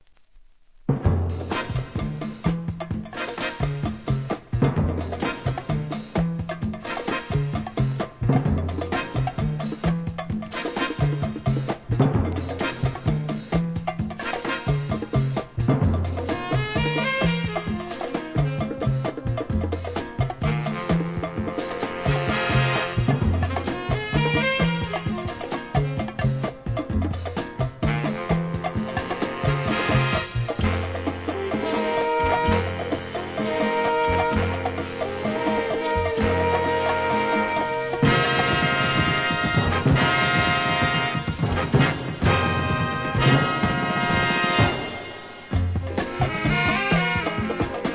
店舗 ただいま品切れ中です お気に入りに追加 1973年 ラテンFUNK!!